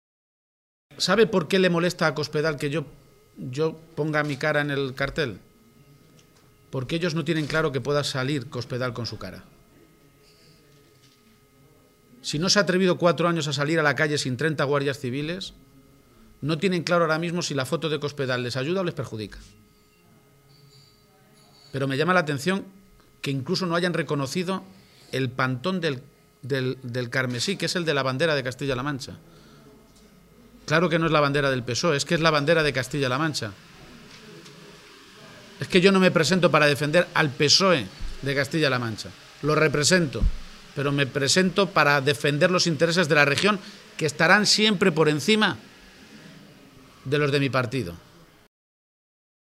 García-Page ha hecho esta afirmación en Guadalajara, donde ha mantenido un encuentro con medios de comunicación para avanzar las principales líneas de sus propuestas para Castilla-La Mancha, mientras Cospedal estaba en una reunión interna del PP convocada para analizar los desastrosos resultados de las elecciones andaluzas, “y convertida en el eje central de todos los problemas, también dentro de su partido”.